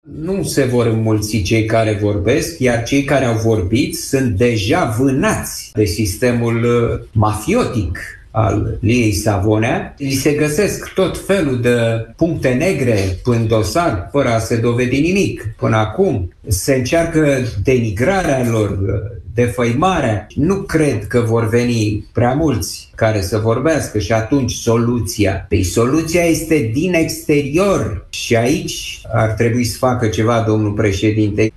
Într-o intervenție la Europa FM, acesta a condamnat actualul sistem de justiție și a salutat protestele de aseară.